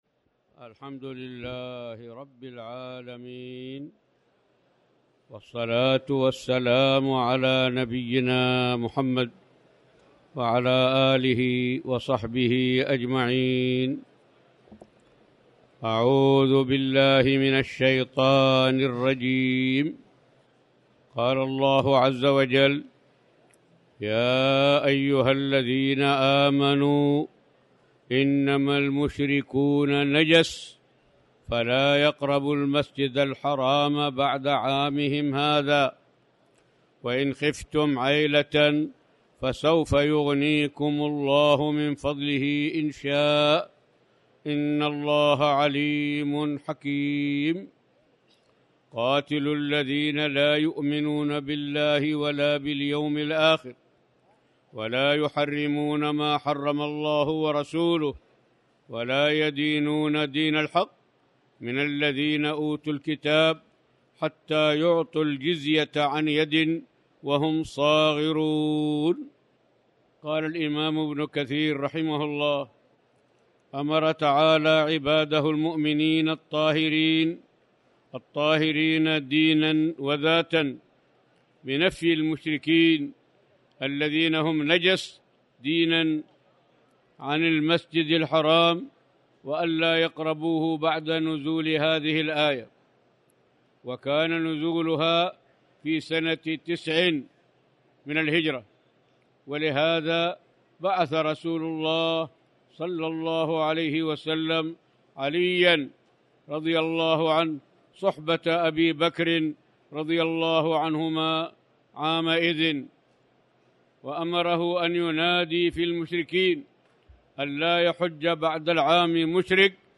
تاريخ النشر ٢٣ رمضان ١٤٣٩ هـ المكان: المسجد الحرام الشيخ